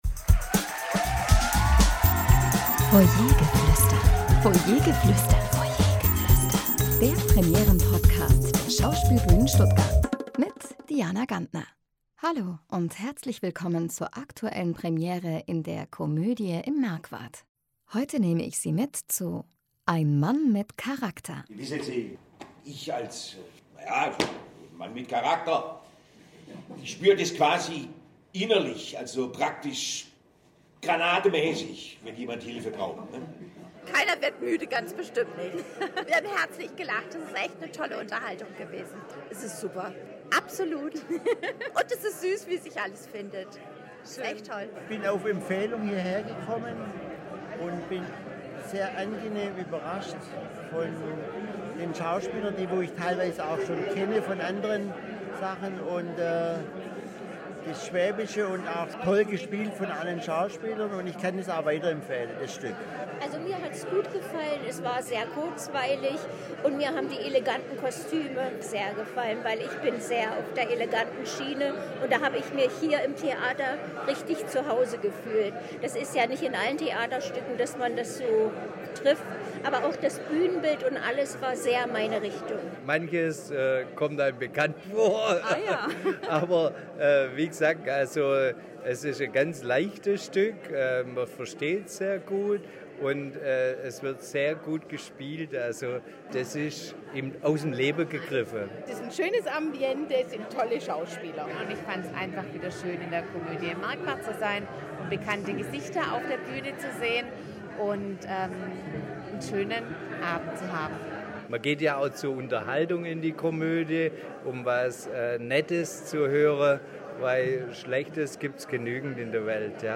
Zuschauerstimmen zur Premiere von “Ein Mann mit Charakter”